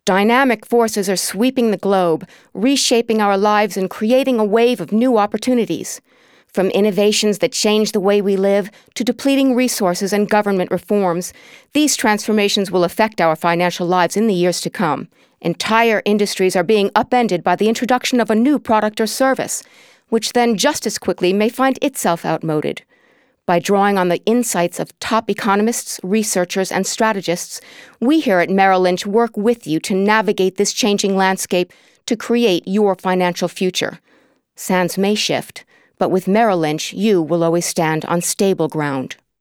Soft-spoken, genuine, animated, intelligent, confident, soothing, caring, conversational.
Sprechprobe: Industrie (Muttersprache):